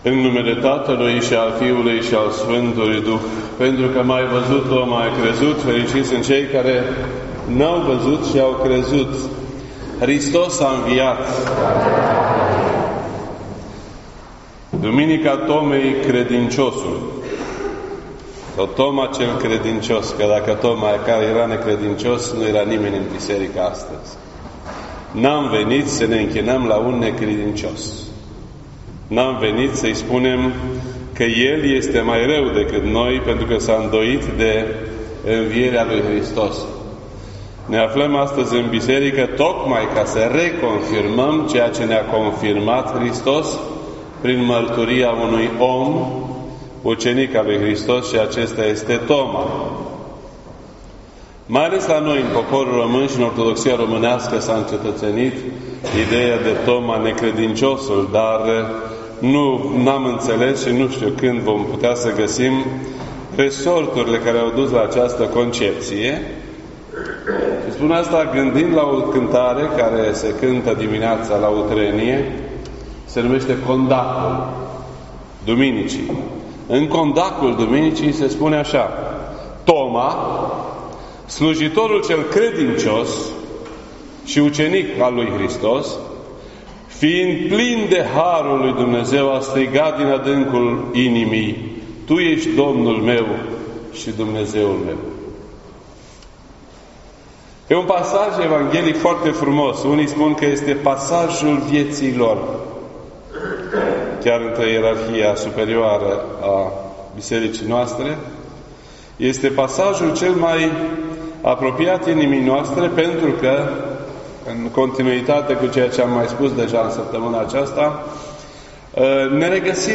2019 at 7:29 PM and is filed under Predici ortodoxe in format audio .